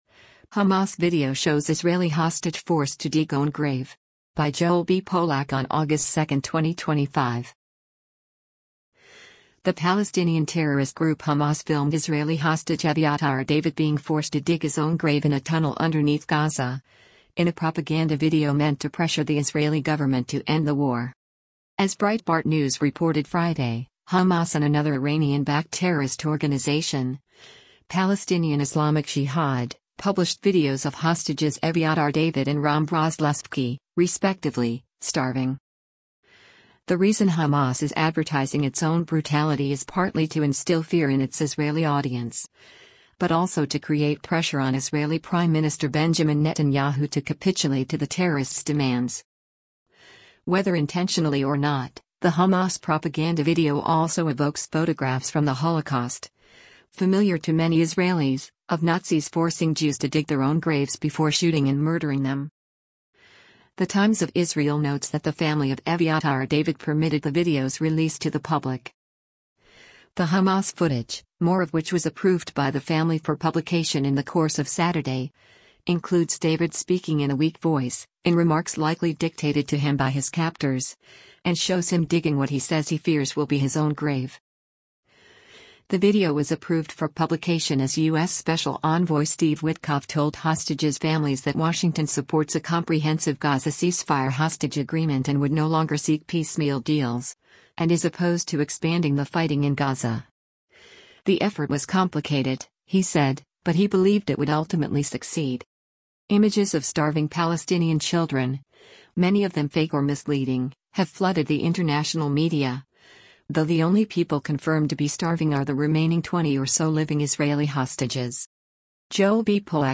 speaking in a weak voice, in remarks likely dictated to him by his captors, and shows him digging what he says he fears will be his own grave.